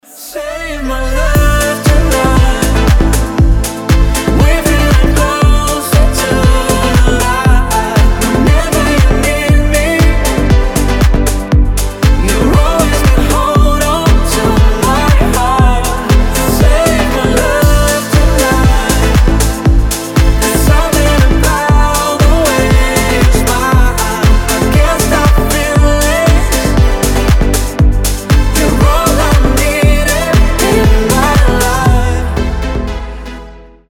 красивые
дуэт
Качественная поп музыка на звонок телефона